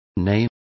Complete with pronunciation of the translation of nay.